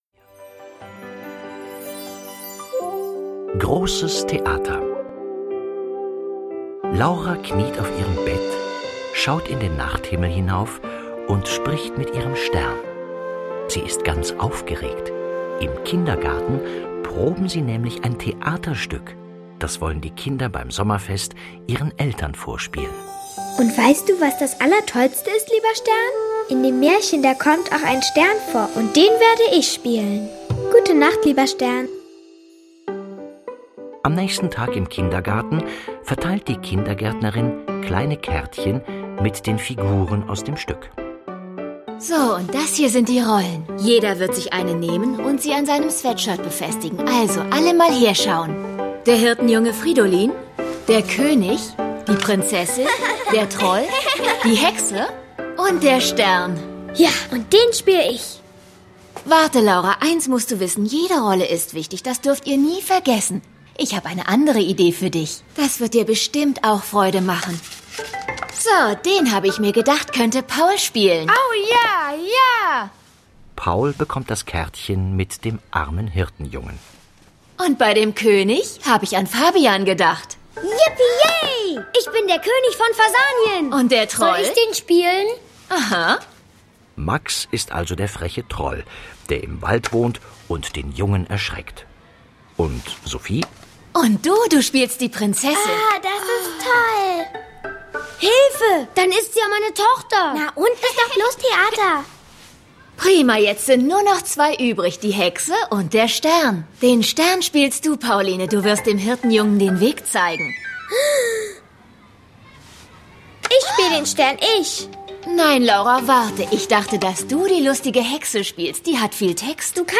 Tonspur der TV-Serie, Folge 6.